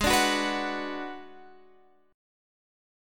Abm7b5 chord